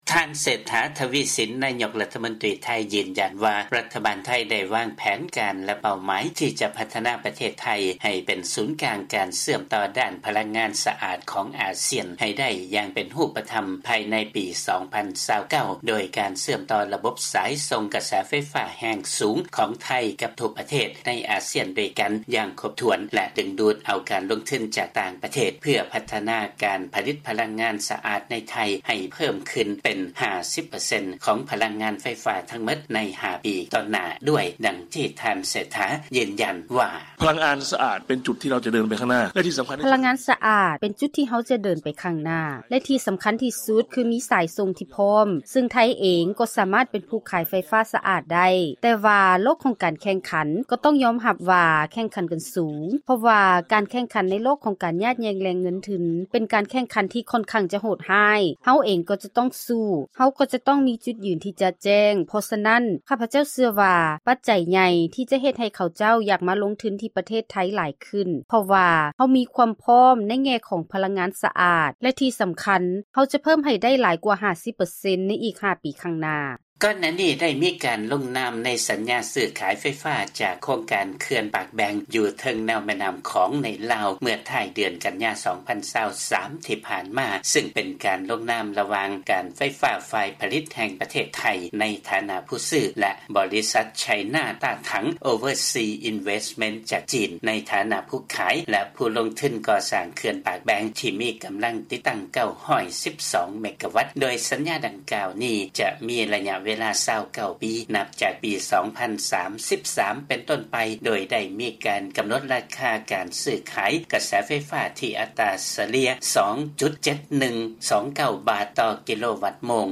ເຊີນຮັບຟັງລາຍງານ ກ່ຽວກັບ ໄທ ວາງແຜນຈະເປັນສູນກາງການເຊື່ອມຕໍ່ດ້ານພະລັງງານສະອາດຂອງອາຊຽນໃຫ້ໄດ້ໃນ 5 ປີ ຕໍ່ໜ້າ.